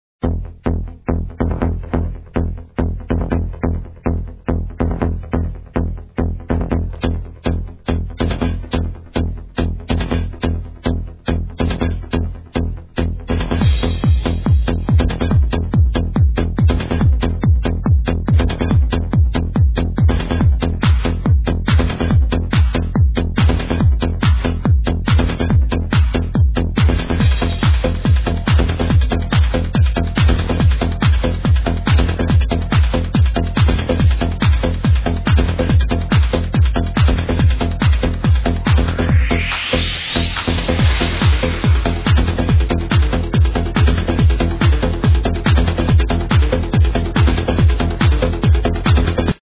ID This happy soundin Trance Song?